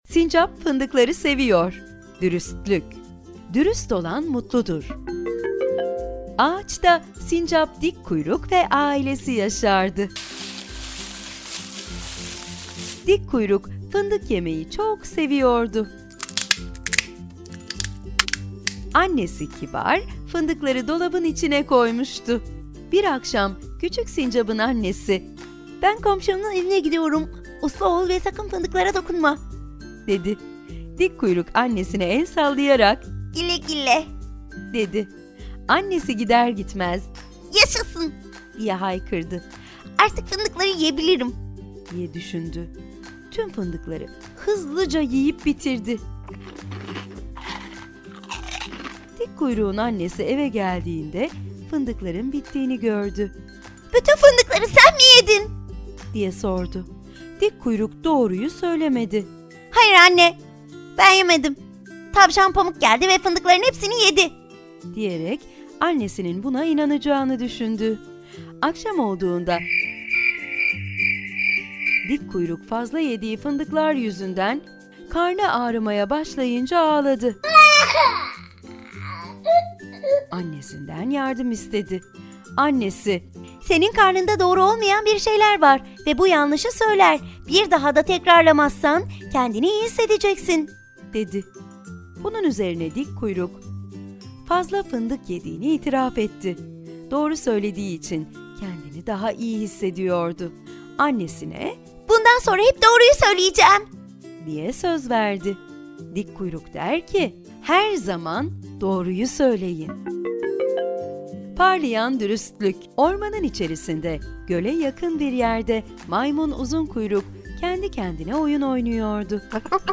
Sesli Kitap